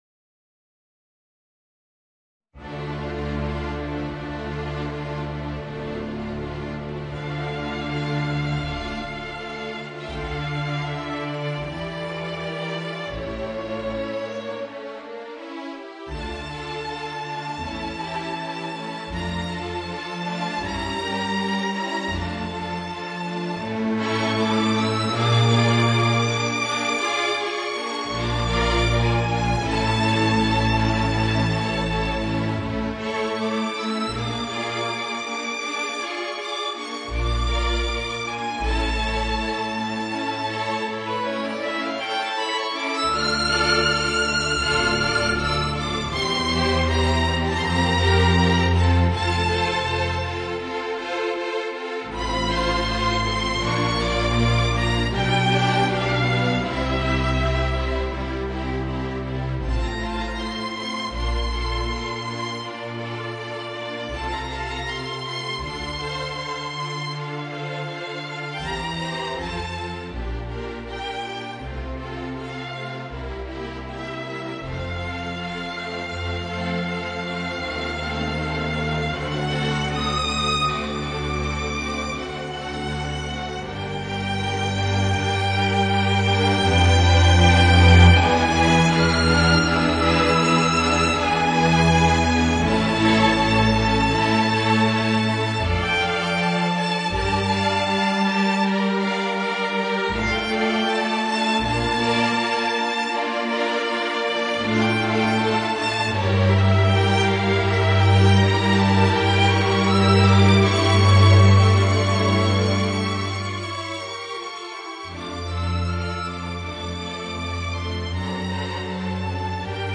Voicing: Alto Saxophone and String Orchestra